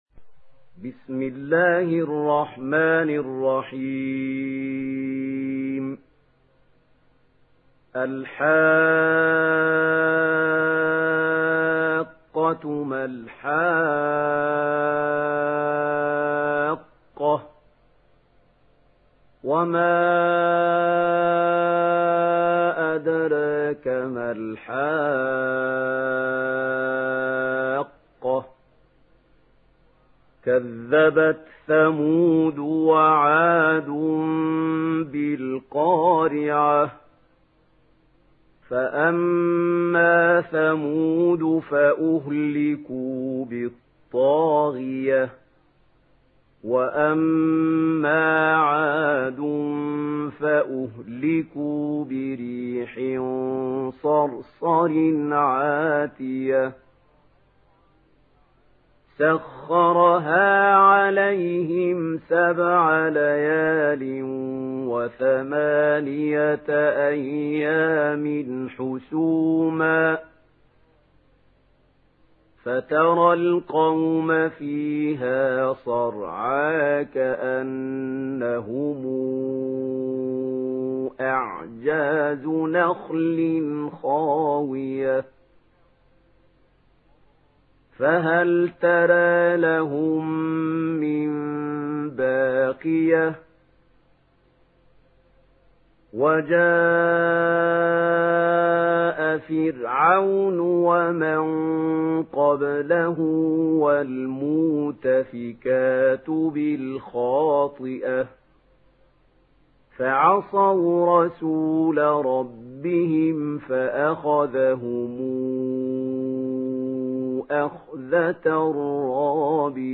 Hakka Suresi İndir mp3 Mahmoud Khalil Al Hussary Riwayat Warsh an Nafi, Kurani indirin ve mp3 tam doğrudan bağlantılar dinle
İndir Hakka Suresi Mahmoud Khalil Al Hussary